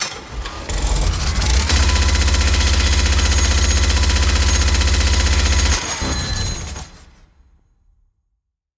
Complex Minigun Spin-Up Firing
Battle Cinematic Combat Complex Fallout Firearm Foley Game sound effect free sound royalty free Movies & TV